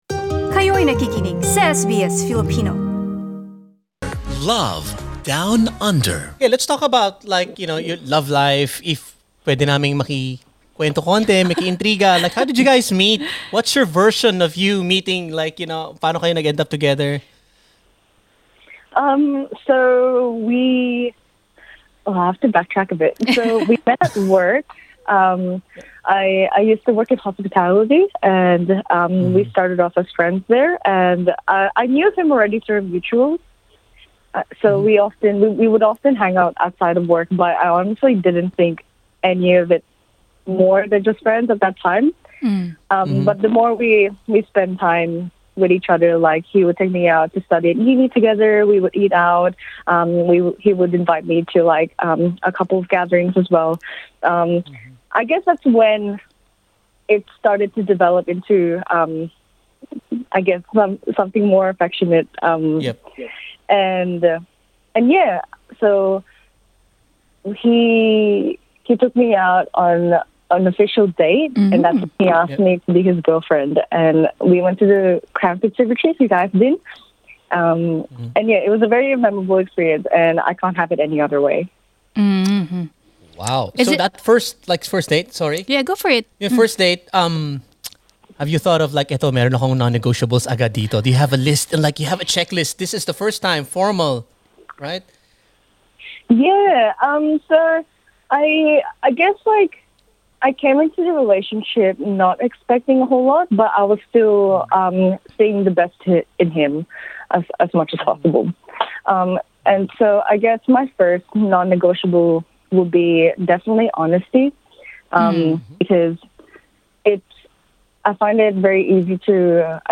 Sa pakikipanayam sa SBS Filipino love down under